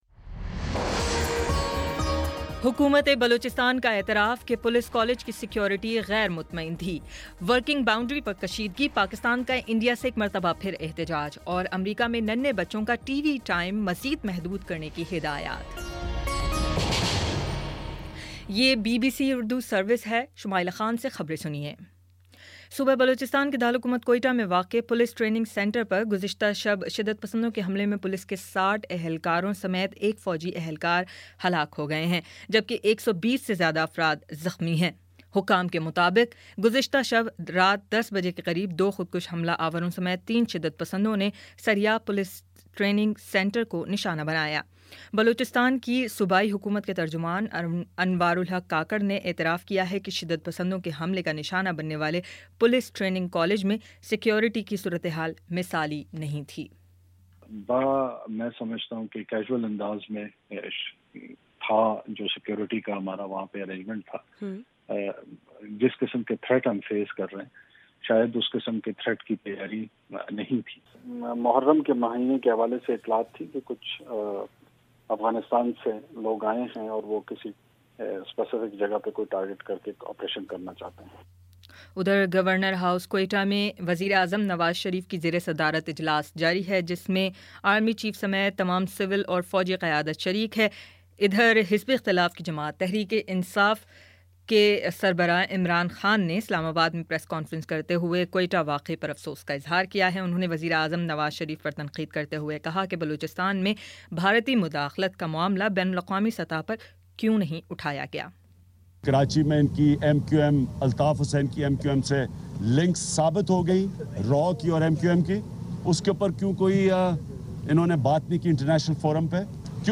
اکتوبر 25 : شام پانچ بجے کا نیوز بُلیٹن